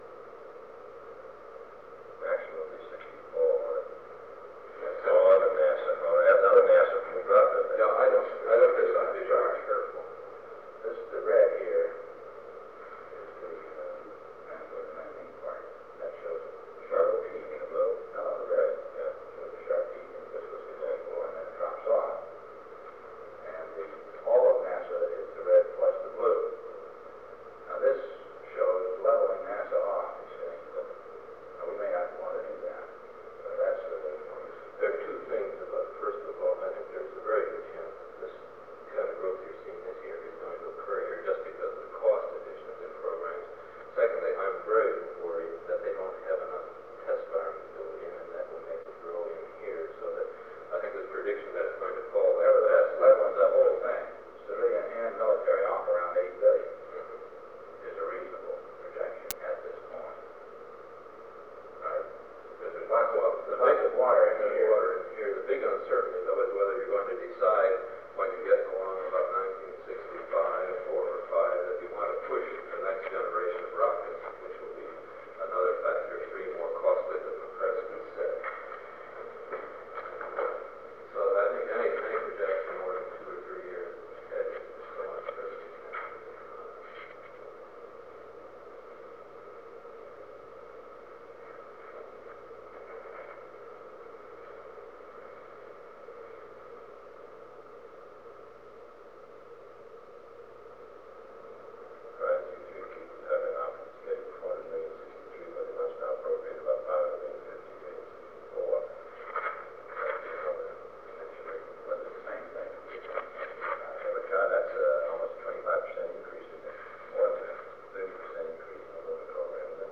Meeting on the NASA Budget
Secret White House Tapes | John F. Kennedy Presidency Meeting on the NASA Budget Rewind 10 seconds Play/Pause Fast-forward 10 seconds 0:00 Download audio Previous Meetings: Tape 121/A57.